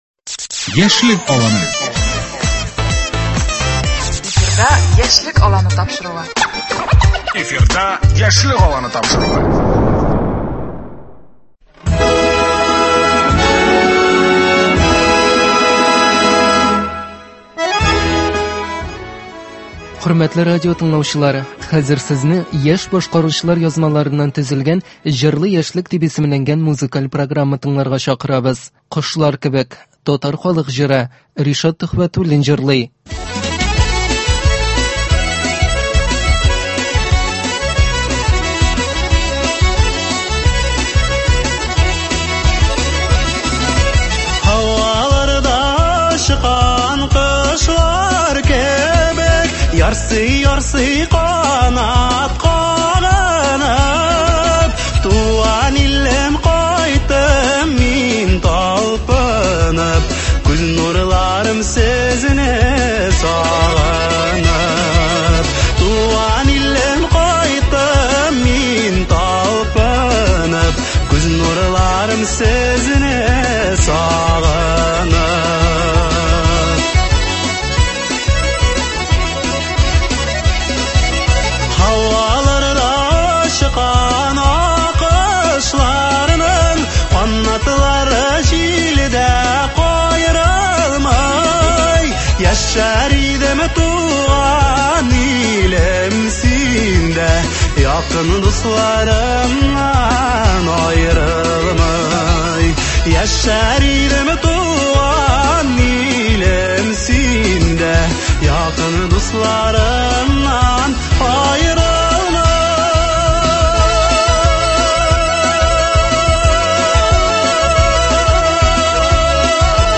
Сезне тапшыруыбызның «Җырлы яшьлек» сәхифәсен тыңларга чакырабыз. Без аны яшь башкаручылар язмаларыннан төзедек.